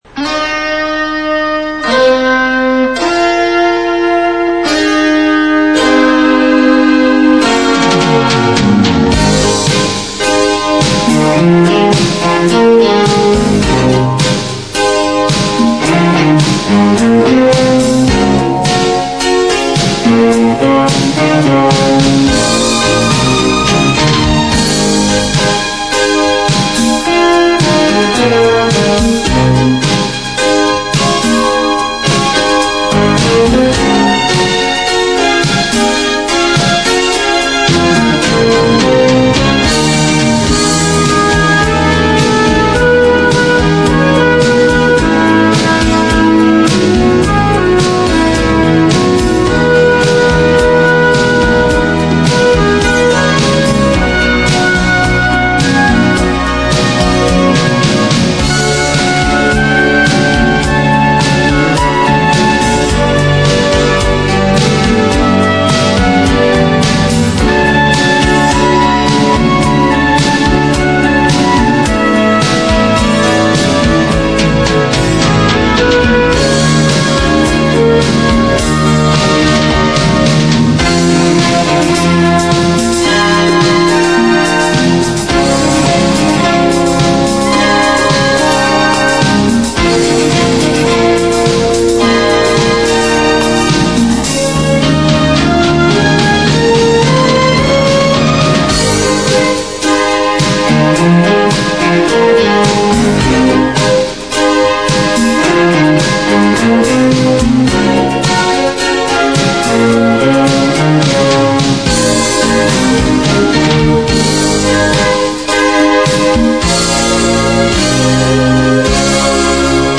Closing credits of the original broadcast